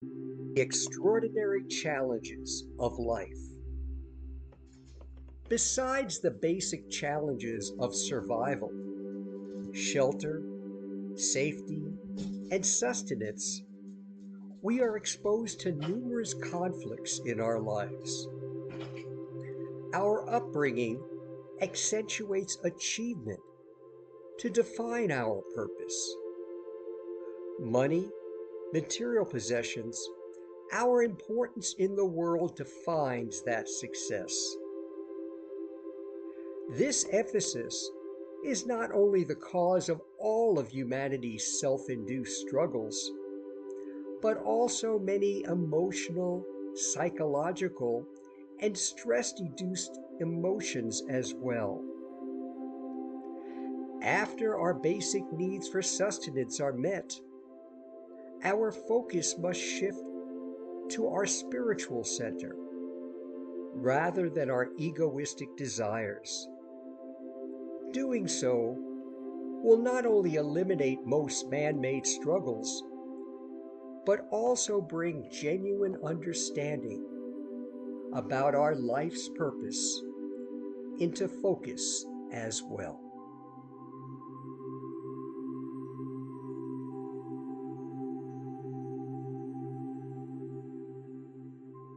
An audio spiritual reflection: